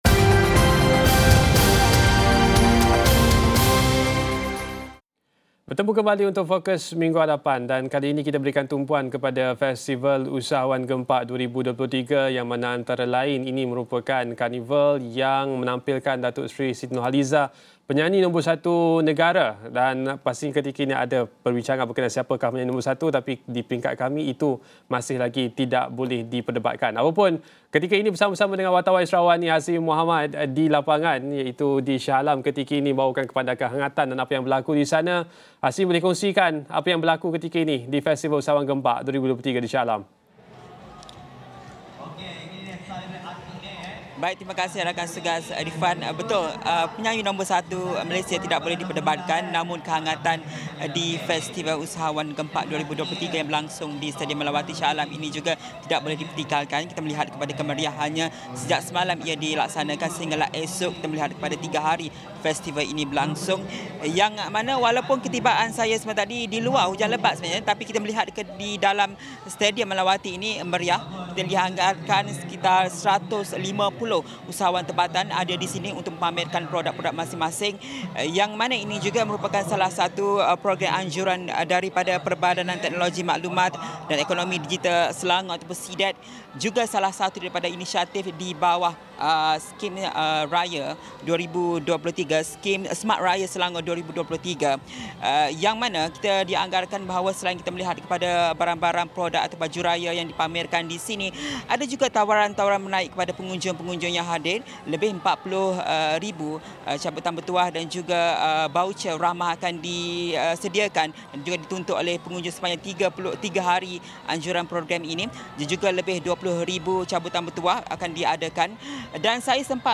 di Stadium Malawati, Shah Alam bersempena dengan Festival Usahawan Gempak 2023.